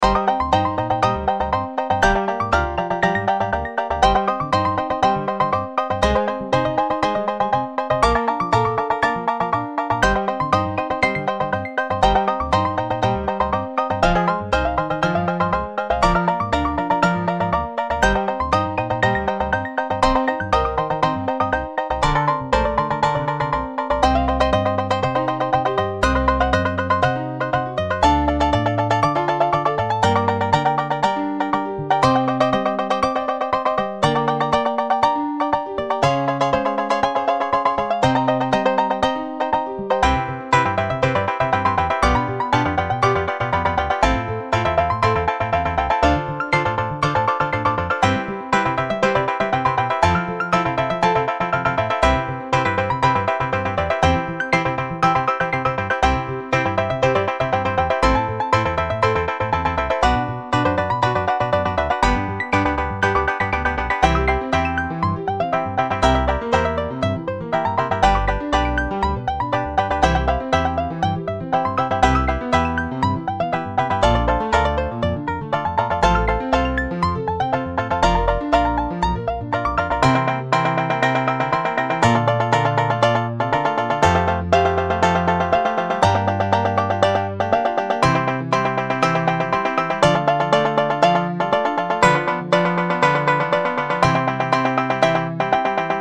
BGM
バンジョー、ピアノ、アコースティックベース